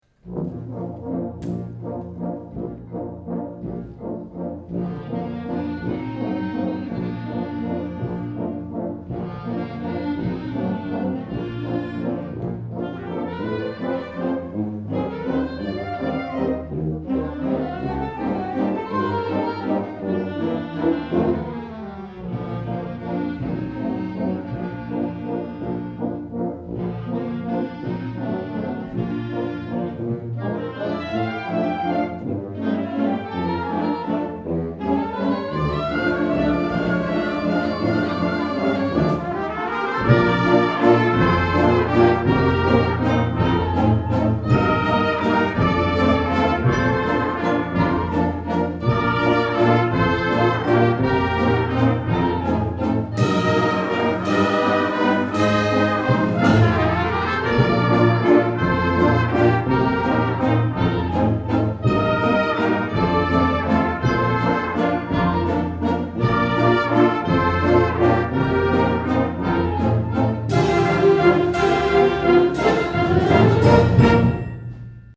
Below are music excerpts from some of our concerts.
2009 Winter Concert
December 20, 2009 - San Marcos High School